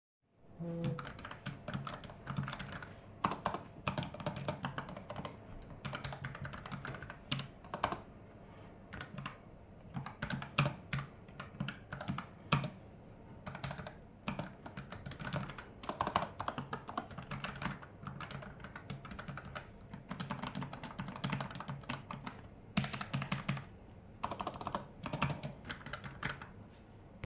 Logitech G515 Lightspeed TKL — низькопрофільна бездротова механічна клавіатура з перемикачами GL Brown та шумопоглинаючою піною